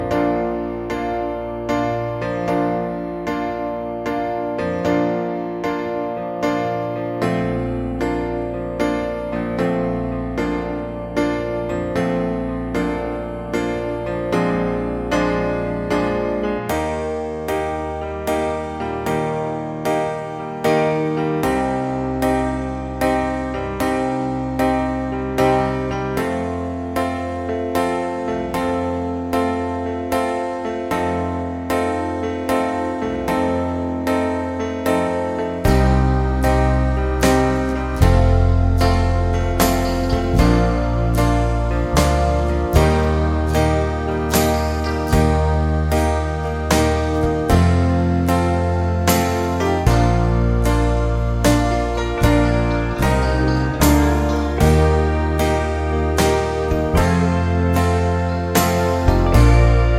for solo male Pop (1980s) 4:35 Buy £1.50